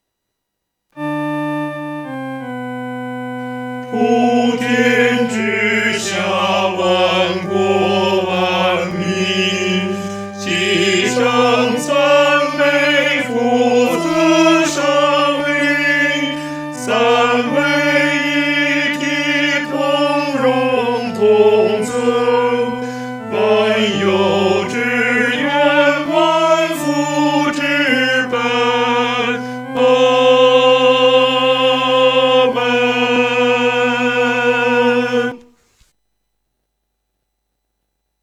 合唱
男高